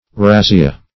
razzia - definition of razzia - synonyms, pronunciation, spelling from Free Dictionary
razzia.mp3